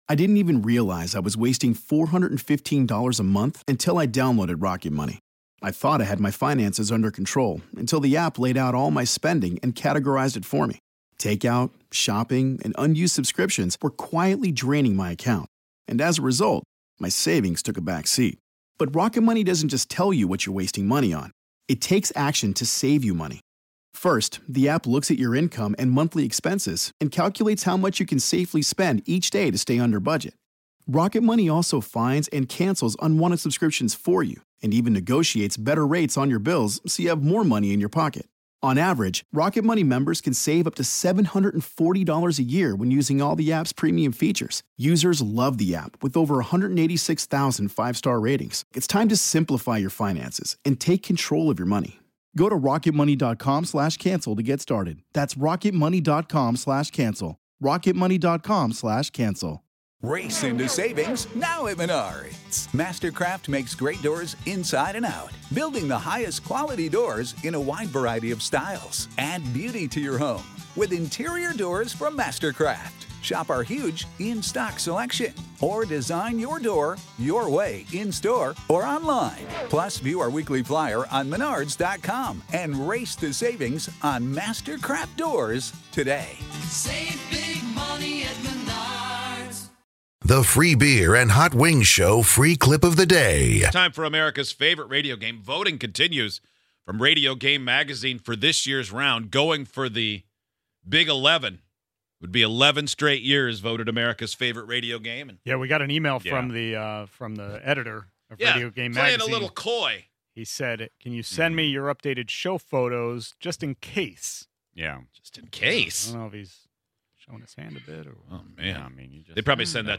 One of our contestants had some background noise happening and had us all in stitches.